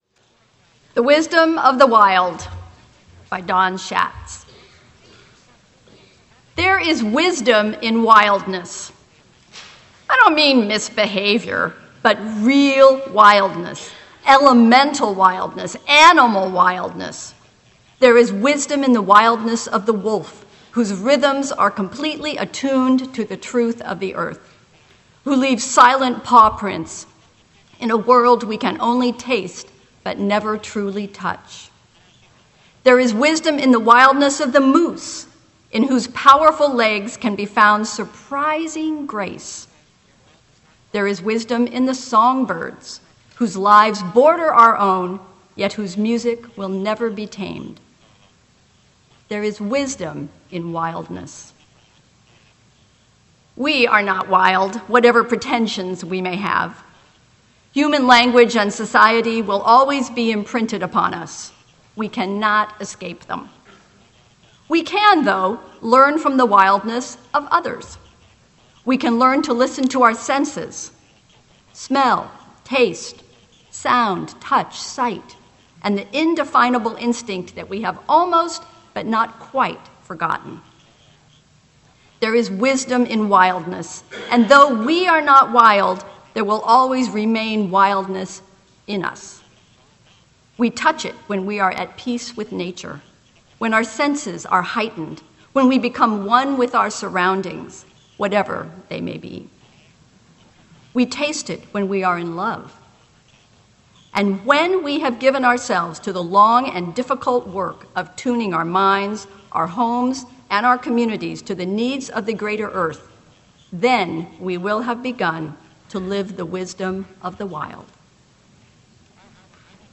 Unitarian Universalist Society of Sacramento